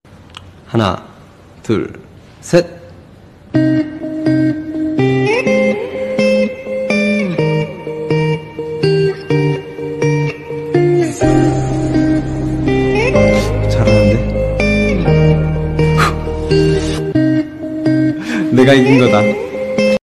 AHAHAHAHA!!! sound effects free download